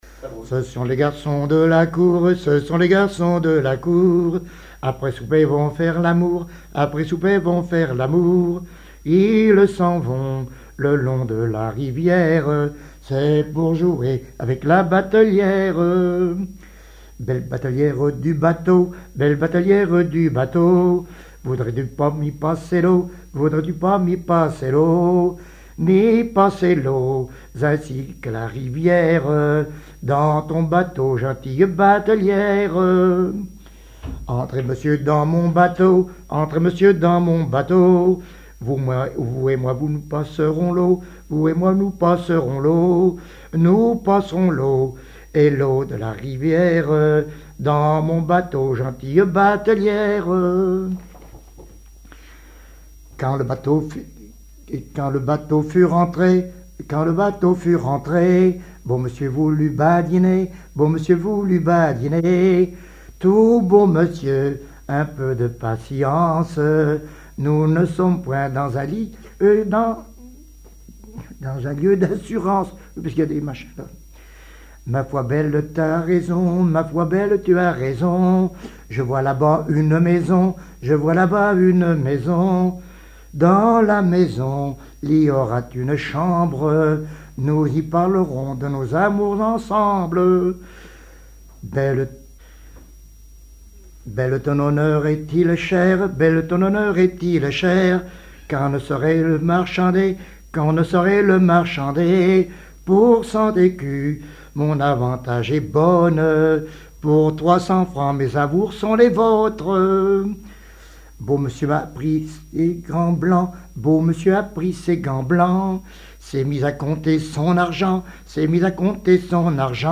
Chansons populaires et témoignages
Pièce musicale inédite